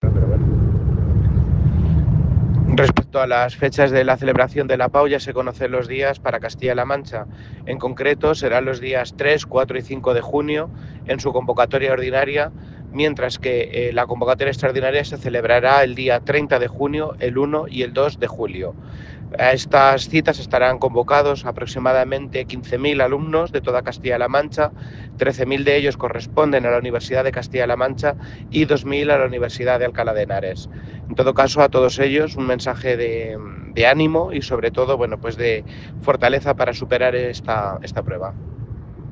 Consejería de Educación, Cultura y Deportes Viernes, 4 Abril 2025 - 10:30am El consejero de Educación, Cultura y Deportes, Amador Pastor, ha avanzado que Castilla-La Mancha celebrará la PAU los días 3, 4 y 5 de junio en convocatoria ordinaria y los días 30 de junio, 1 y 2 de julio en convocatoria extraordinaria. pastor-pau.mp3 Descargar: Descargar